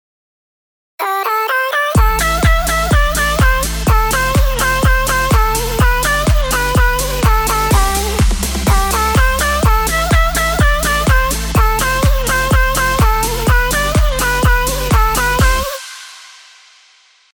今回は例として、ちょっと切ない感じのEDMにしてみます。
つまり、ちょっと切ないコード進行・4つ打ちドラム・シンセでいきます。
歌メロ以外のパートをそれっぽく仮組したのが↓の音源です。